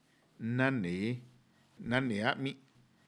Chicahuaxtla Triqui, wordlist, Oaxaca Mexico, language documentation